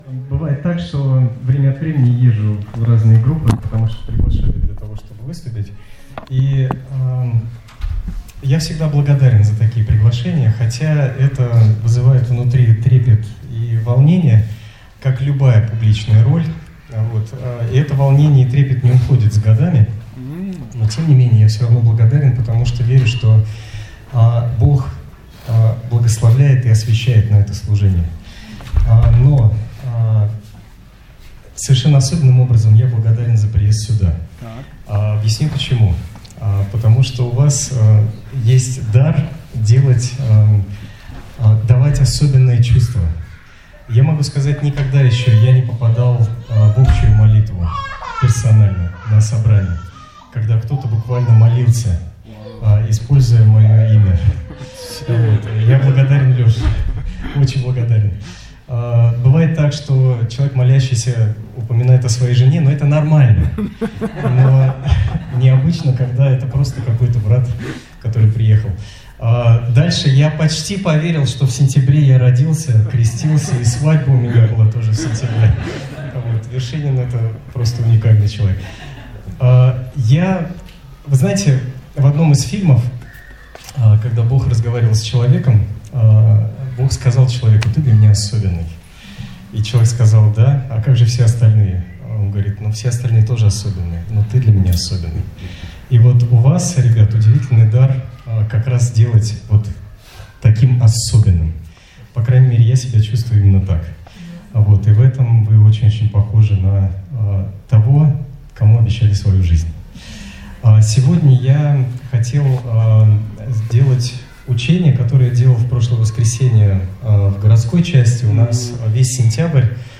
Continue reading ➞ Делайте Всё, Как Для Господа. Проповедь Из Серии о Финансах